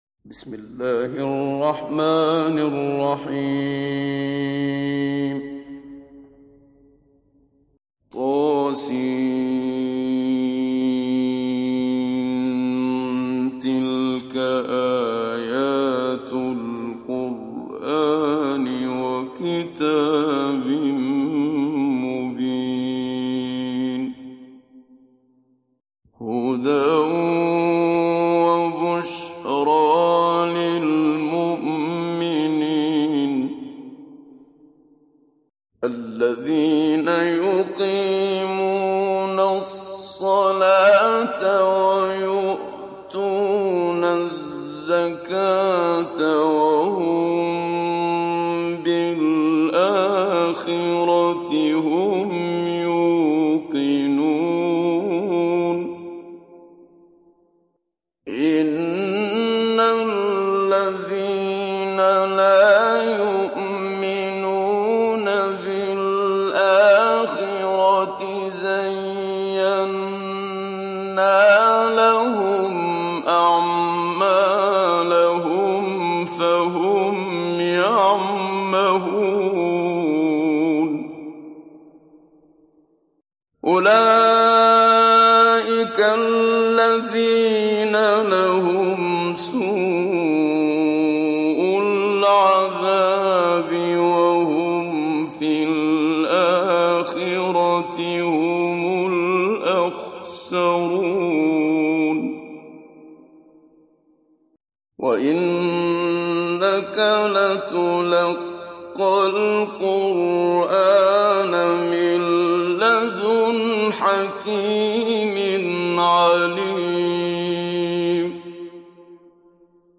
Mujawwad